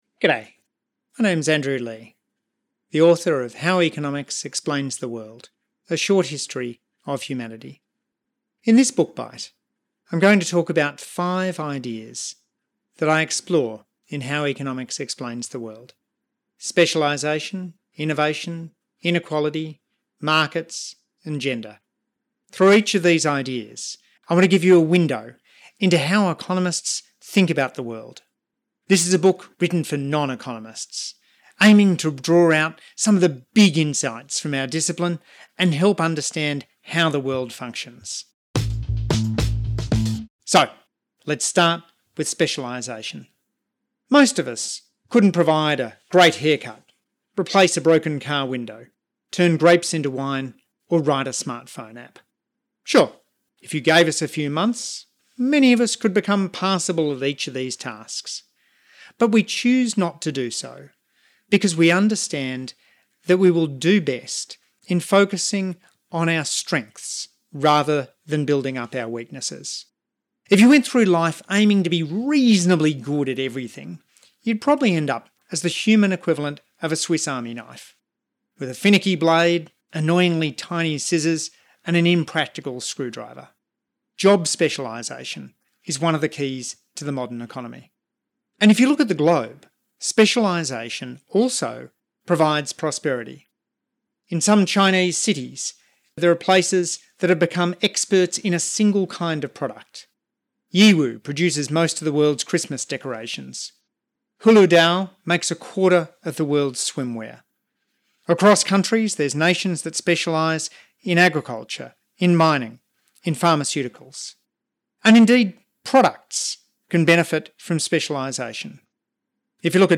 Listen to the audio version—read by Andrew himself—in the Next Big Idea App.